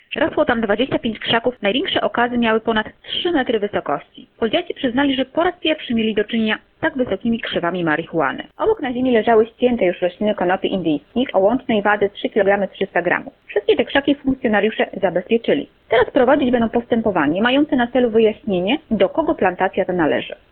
Mówi komisarz